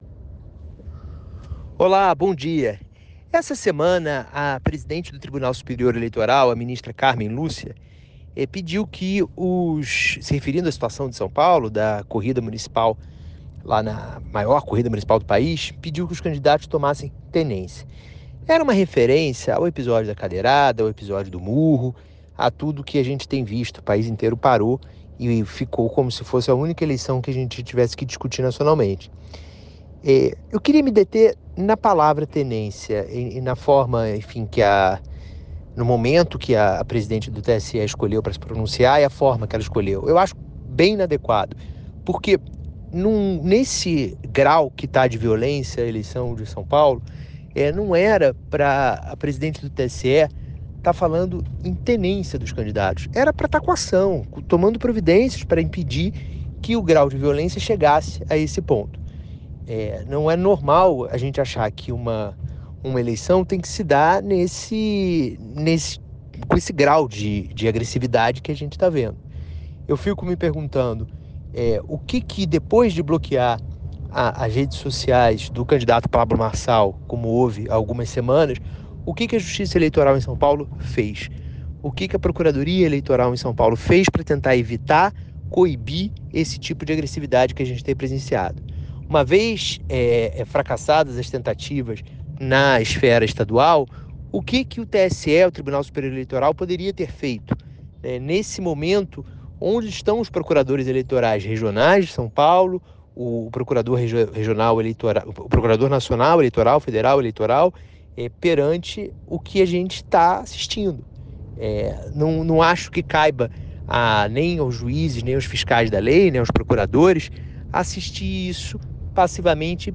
Bastidores, articulações e tudo o que envolve a política brasileira você encontra na coluna do Guilherme Amado. O jornalista comenta os principais assuntos de Brasília de uma forma simples e objetiva.
Categoria: Coluna
Periodicidade: 5 vezes por semana (segunda a sexta-feira), gravada